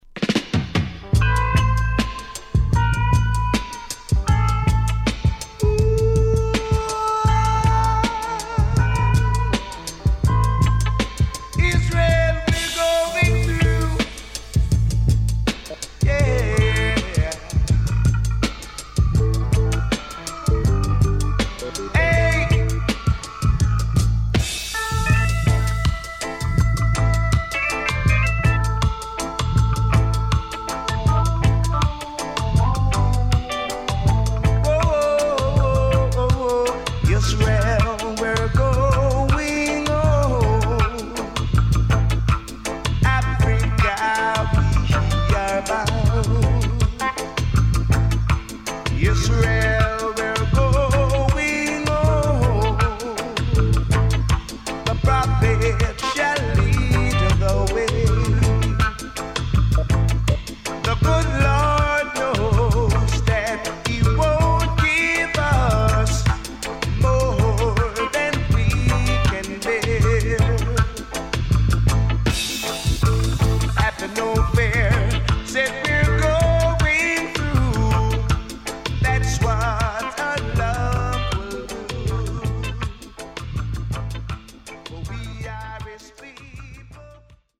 HOME > REISSUE [REGGAE / ROOTS]